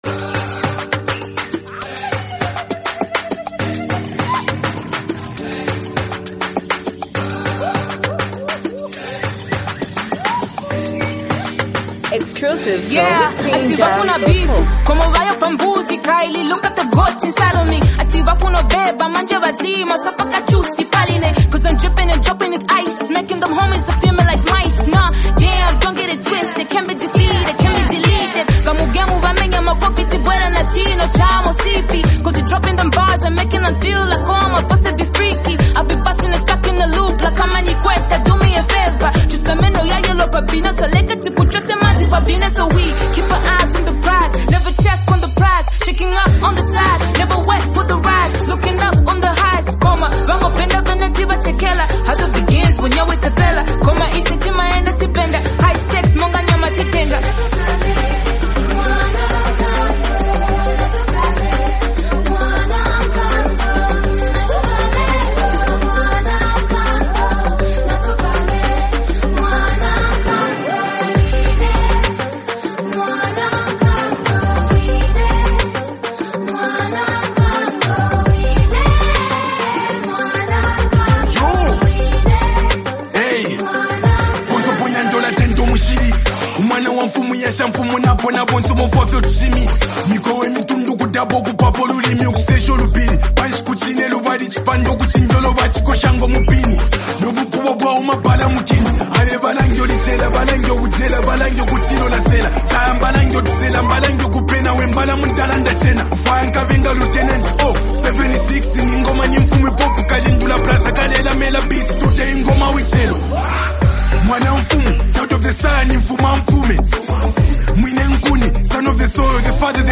a commanding delivery that adds intensity to the record